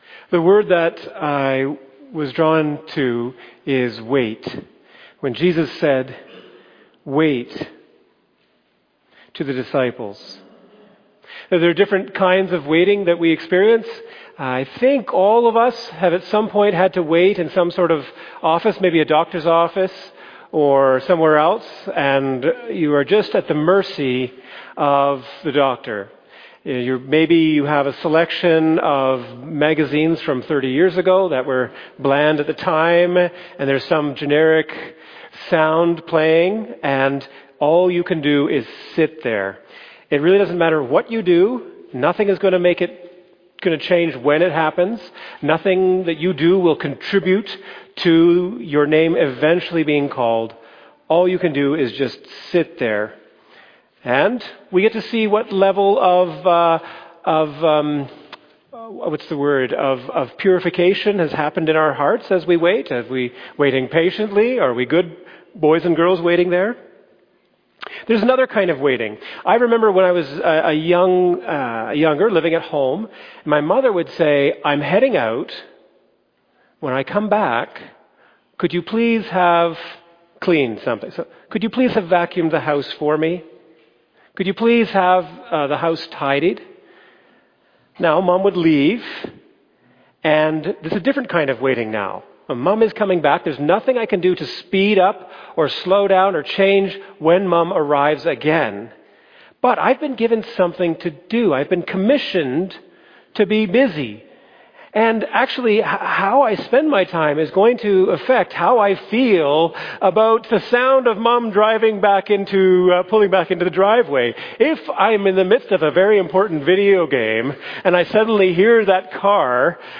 fbcsermon_2025_June1.mp3